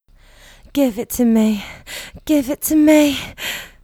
Track 11 - Vocal Give It To Me.wav